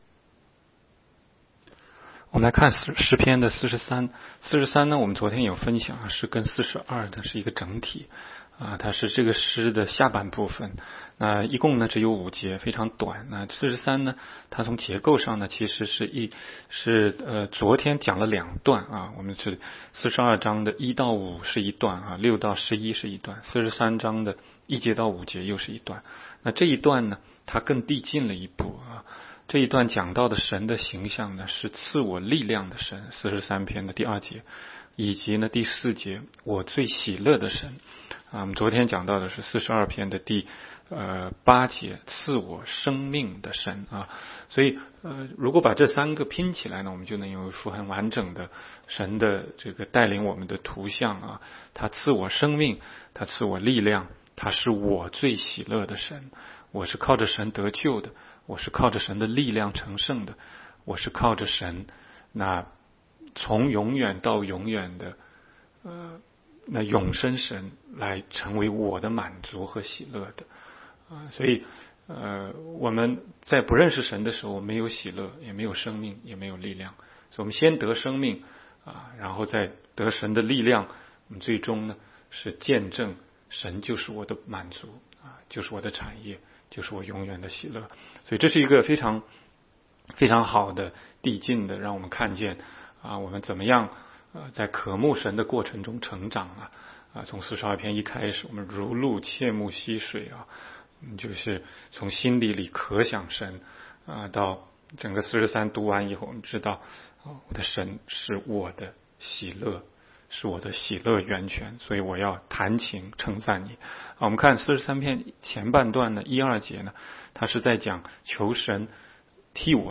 16街讲道录音 - 每日读经-《诗篇》43章